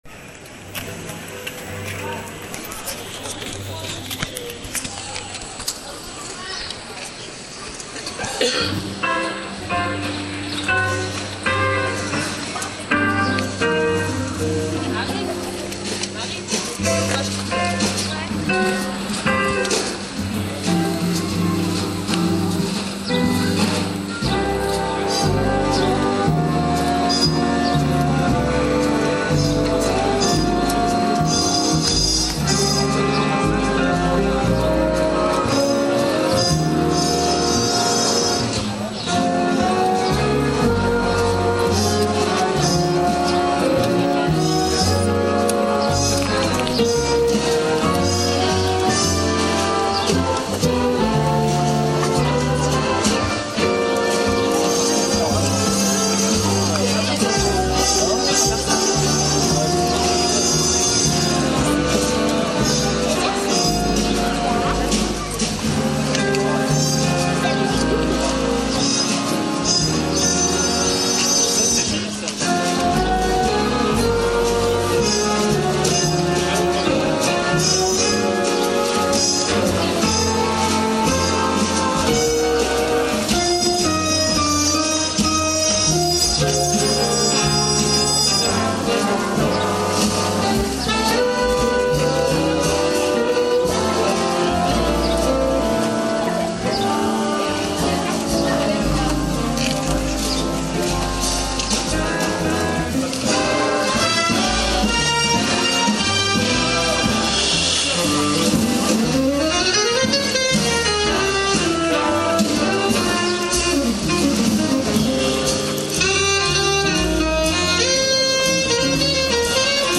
Concert au Parc Floral